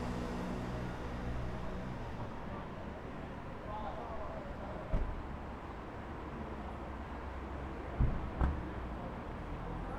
Environmental
UrbanSounds
Streetsounds